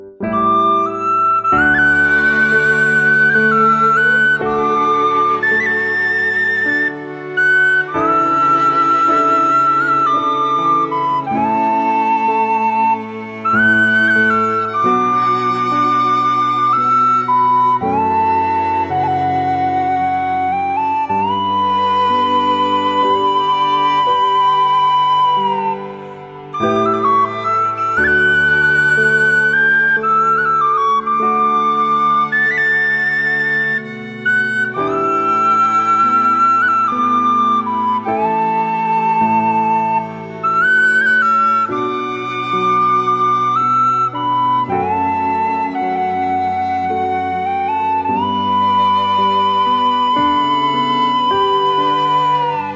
traditional Irish music